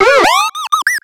Cri de Baudrive dans Pokémon X et Y.